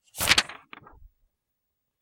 FND 112 "页面翻转
描述：我沙沙作响的页面
声道立体声